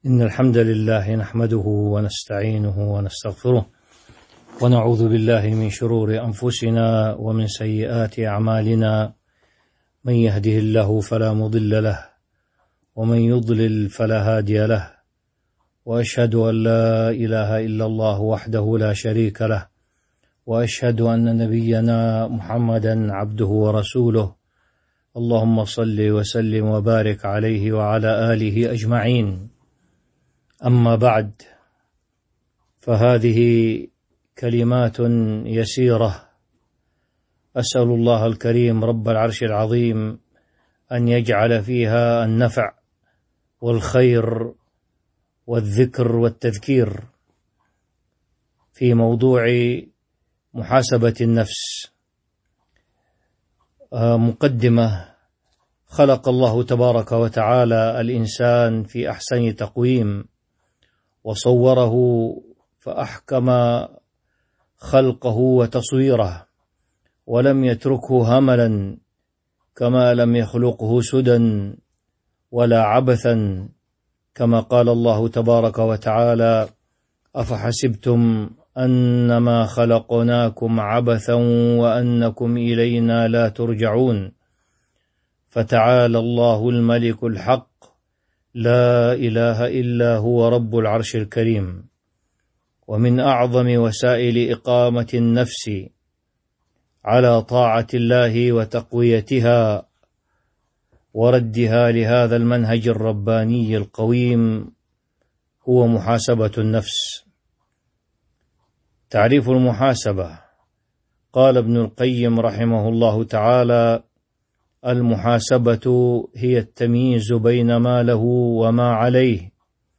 تاريخ النشر ٢٧ رجب ١٤٤٣ هـ المكان: المسجد النبوي الشيخ: فضيلة الشيخ الأستاذ الدكتور أنيس بن أحمد طاهر فضيلة الشيخ الأستاذ الدكتور أنيس بن أحمد طاهر حاسبوا أنفسكم قبل أن تحاسبوا